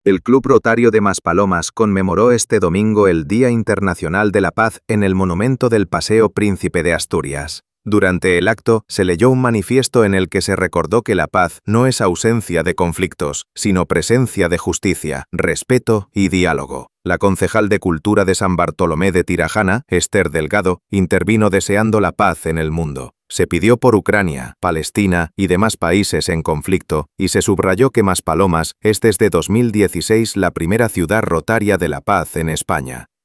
El Club Rotario de Maspalomas conmemoró hoy el Día Internacional de la Paz con un acto celebrado en el monumento a la Paz del Paseo Príncipe de Asturias.